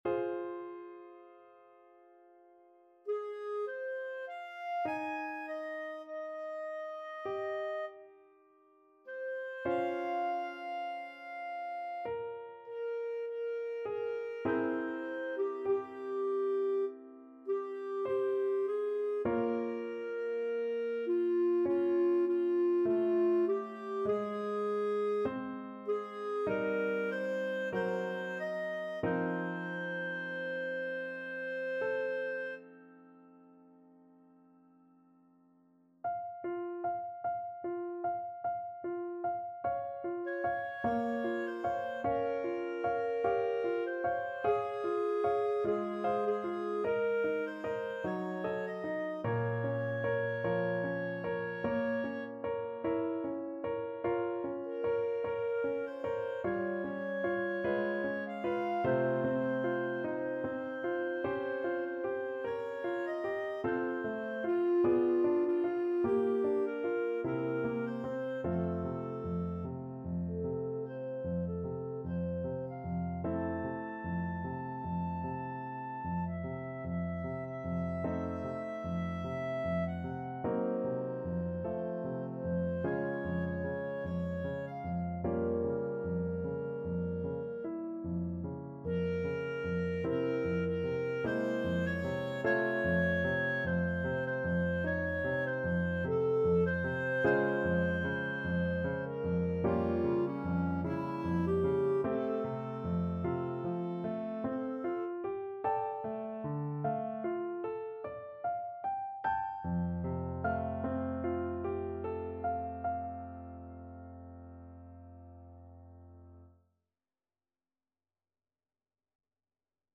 4/4 (View more 4/4 Music)
~ = 100 Lento =50
Clarinet  (View more Easy Clarinet Music)
Classical (View more Classical Clarinet Music)